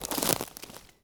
wood_tree_branch_move_04.wav